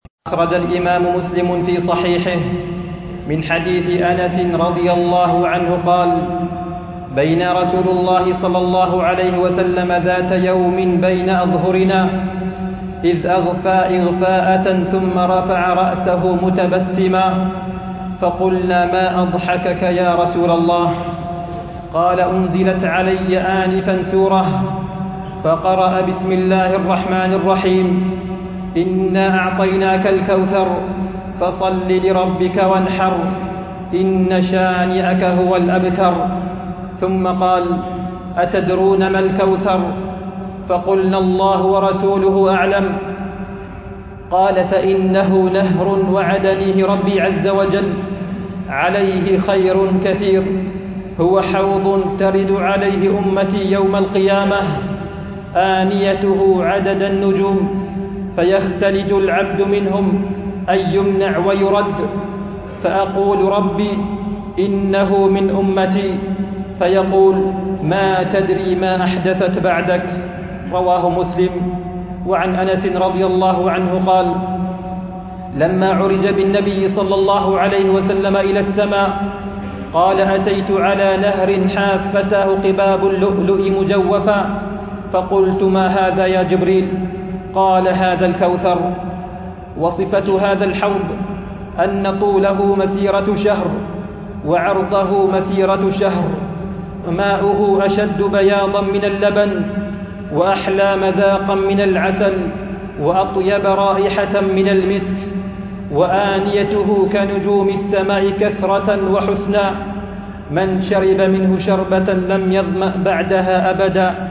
التنسيق: MP3 Mono 22kHz 64Kbps (CBR)